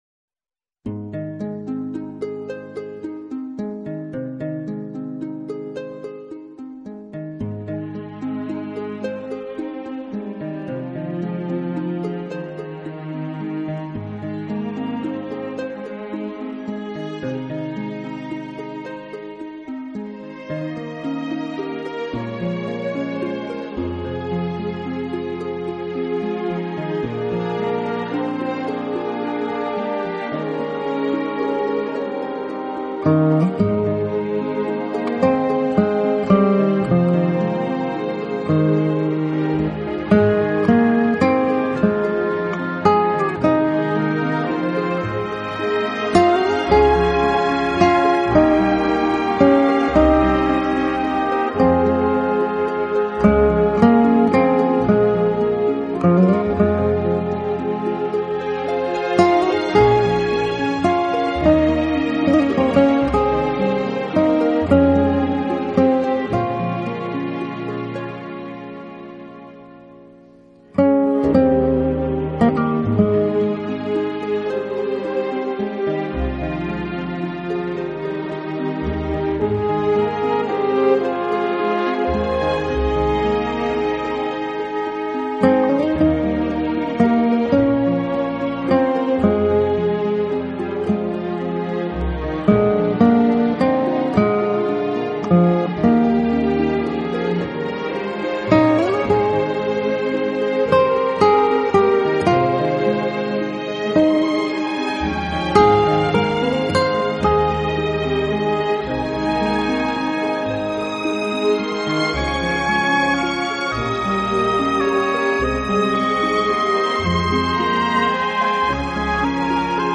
Genre: Instrumental / Classic and romantic guitar music